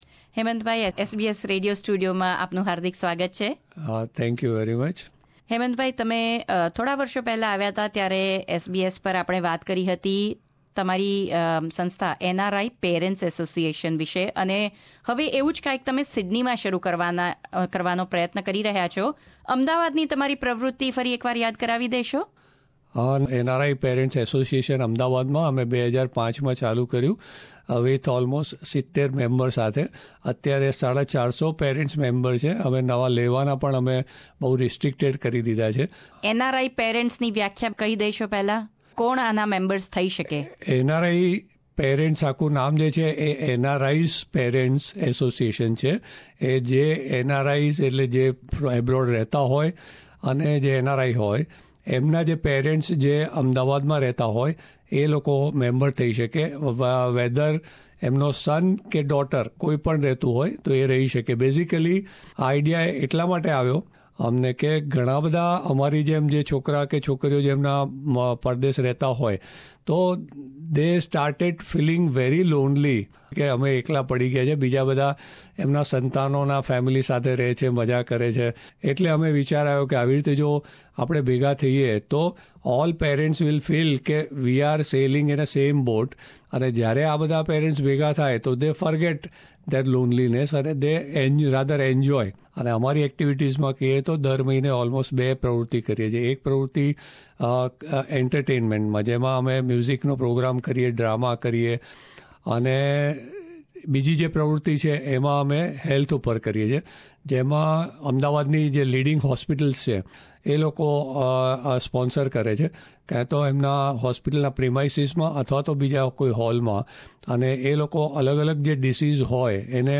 in SBS Studio, Sydney